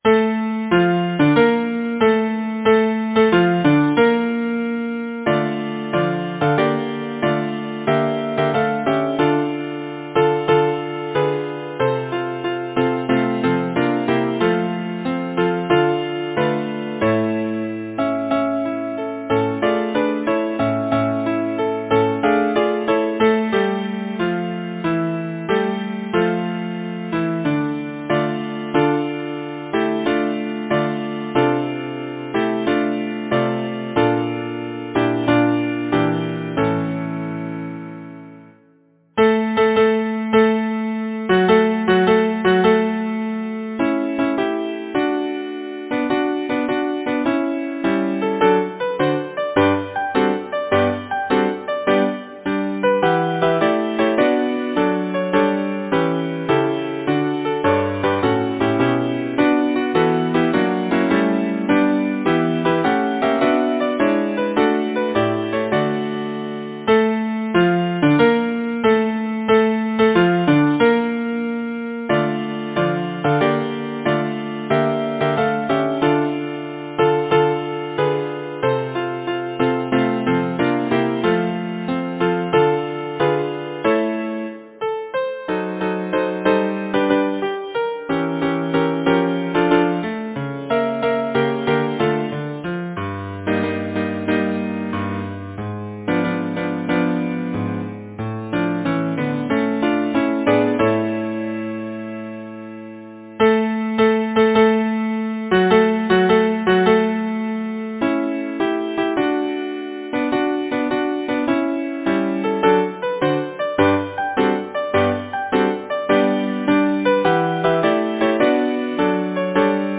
Number of voices: 4vv Voicing: SATB, with minor Tenor divisi. Genre: Secular, Partsong
Language: English Instruments: a cappella or Keyboard